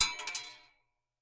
Bullet Shell Sounds
pistol_metal_7.ogg